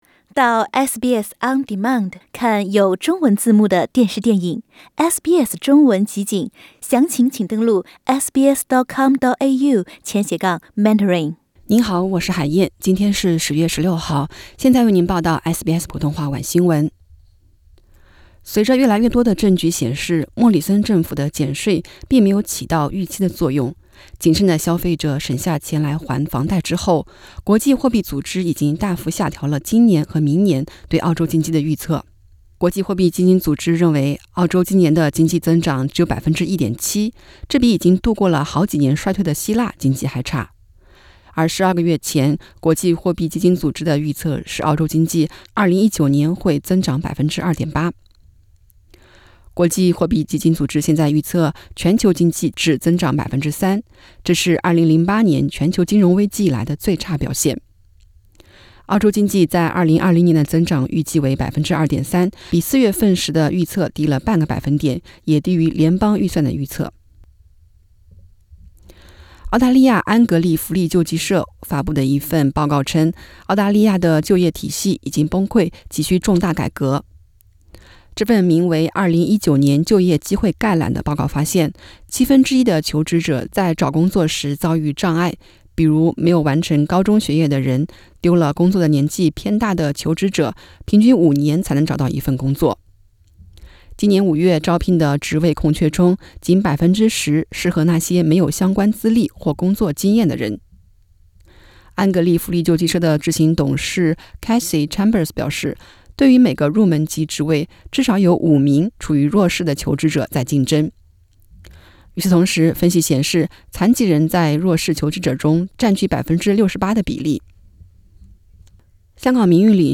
SBS晚新闻（2019年10月16日）